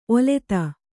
♪ oleta